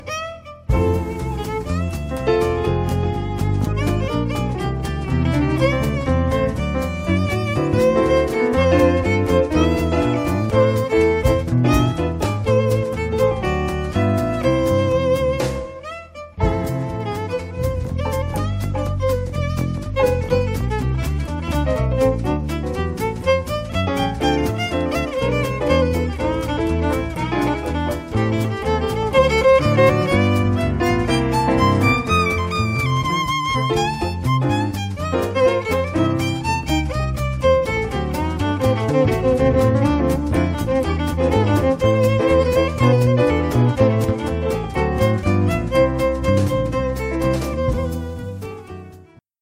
en concert
violon, chant
piano
guitare basse, chant
batterie
guitare, trompette